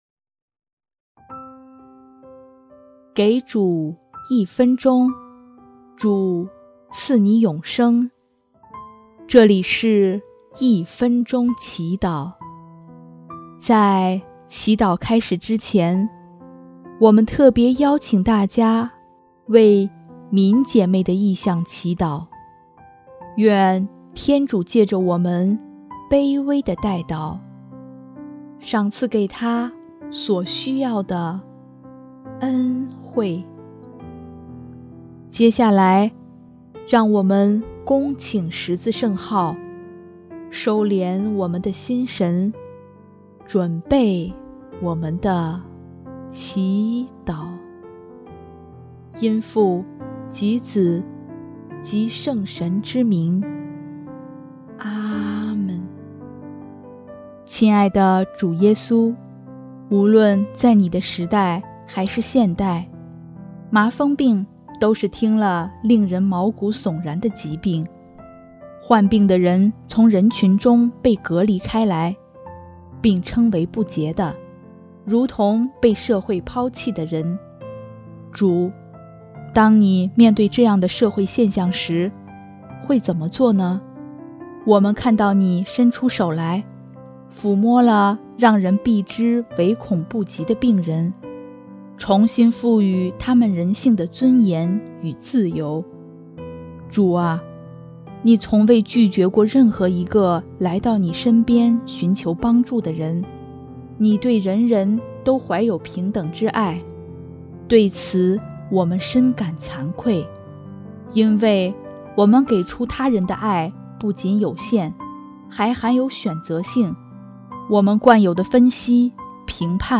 【一分钟祈祷】|2月11日 伸出手，让自然之爱流动